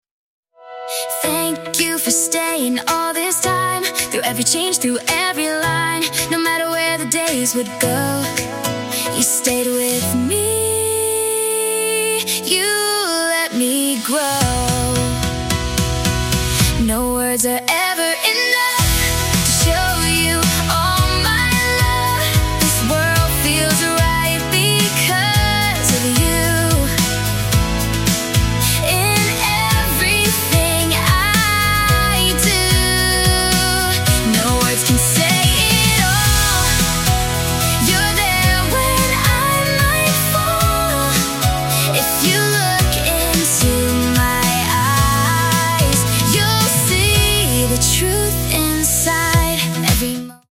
original pop music
unique virtual performers